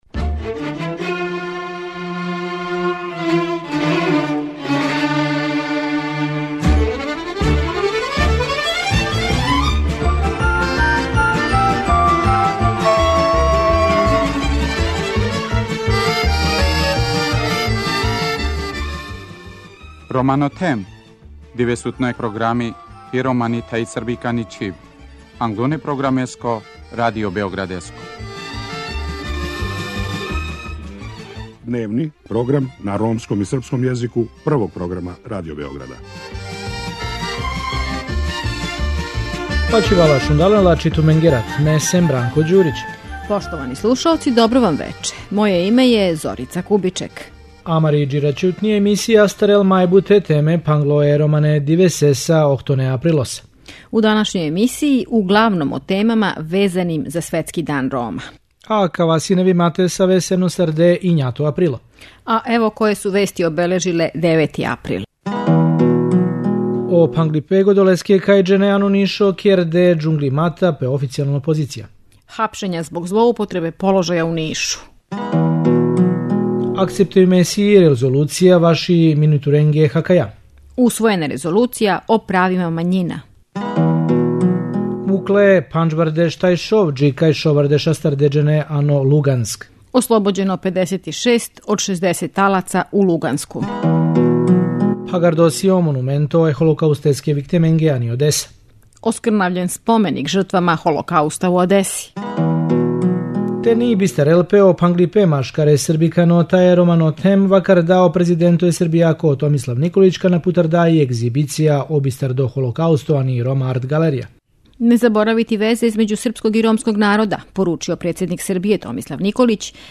Чућете и атмосферу са синоћњег концерта групе Ђелем ђелем.